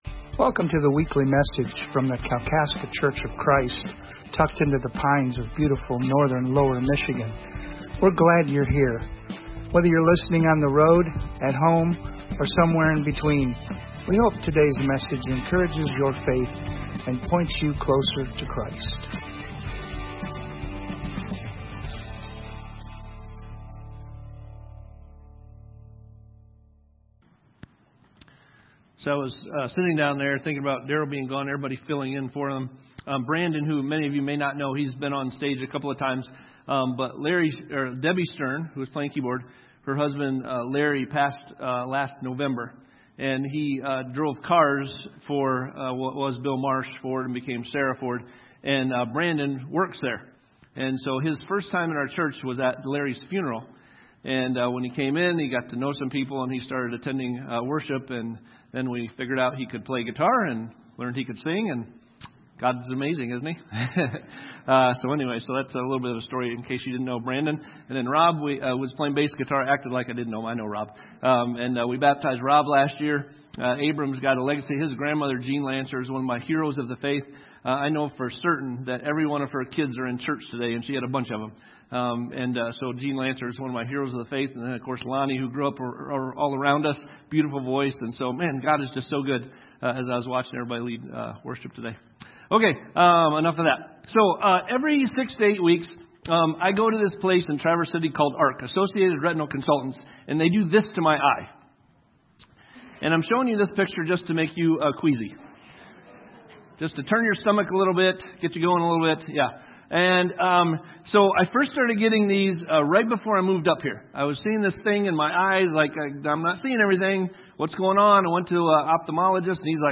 Sunday sermon from Kalkaska Church of Christ on March 29, 2026